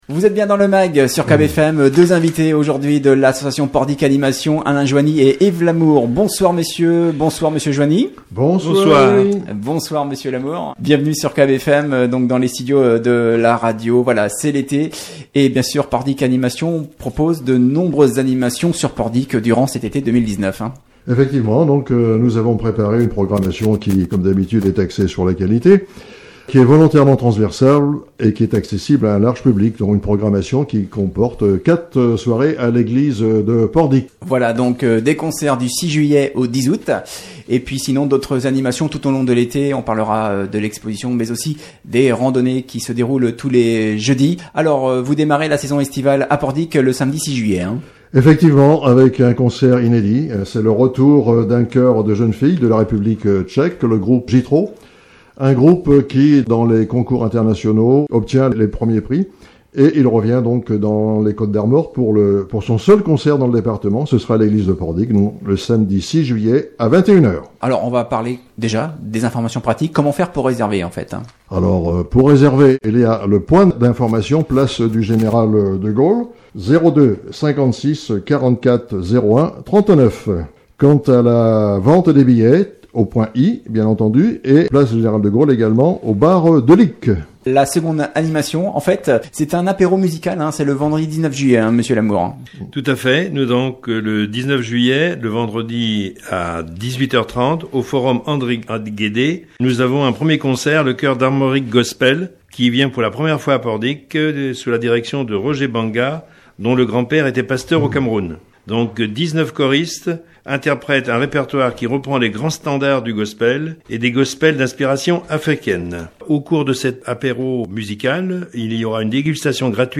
Invités du Mag hier soir